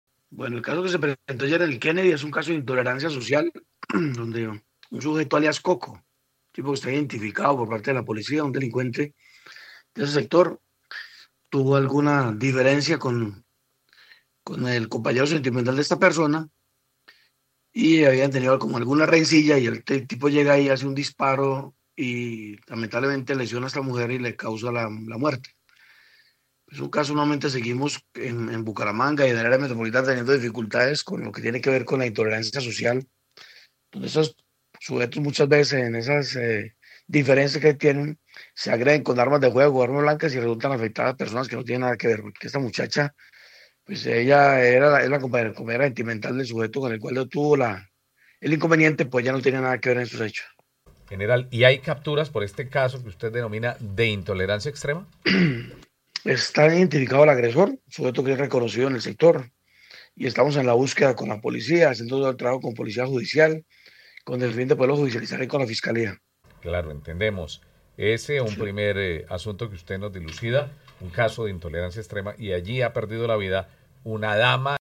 El general William Quintero, comandante de la institución en el área metropolitana reveló en Caracol Radio que un informante alertó sobre un posible hecho criminal.
General William Quintero, comandante de la Policía Metropolitana de Bucaramanga